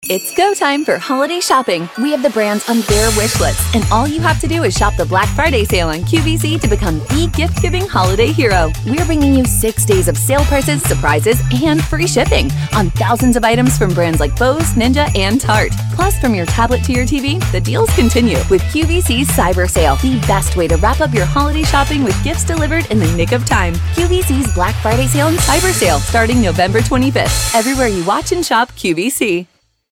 announcer, confident, friendly, high-energy, millennial, perky, promo, retail, upbeat